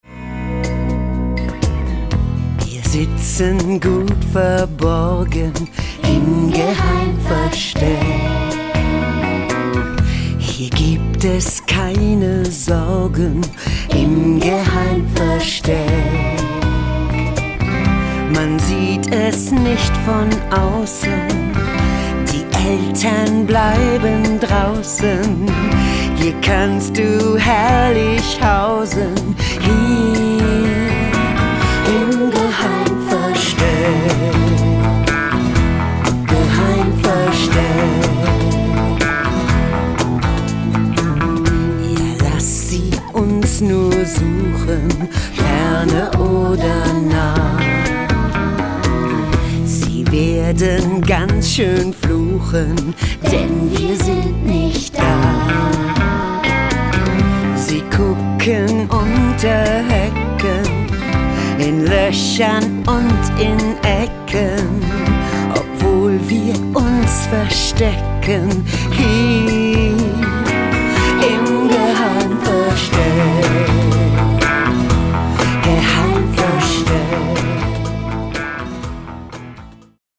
Ein buntes Frühlings-Musical